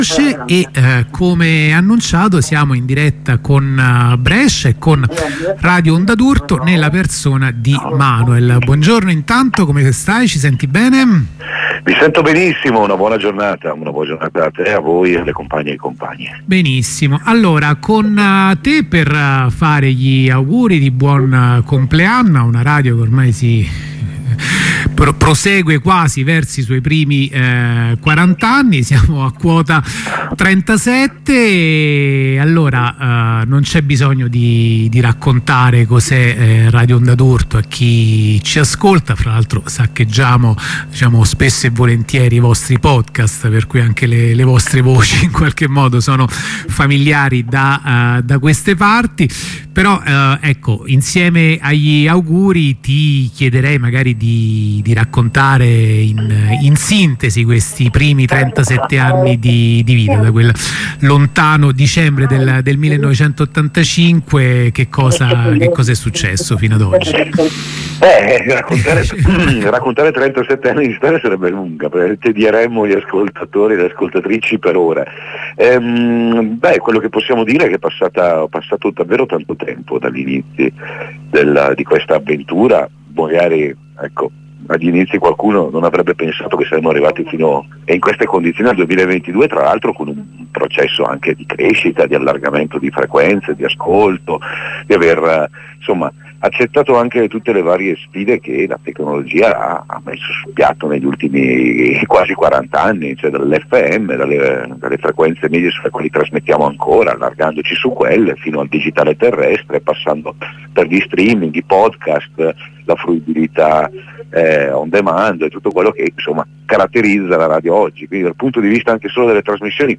Intervento di apertura